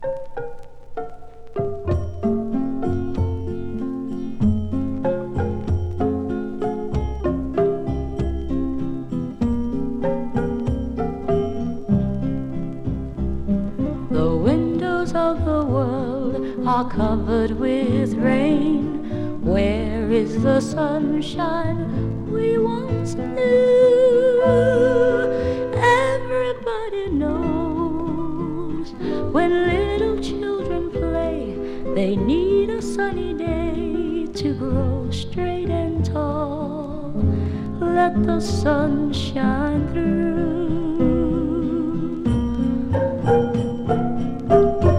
Pop, Soul, Vocal　USA　12inchレコード　33rpm　Stereo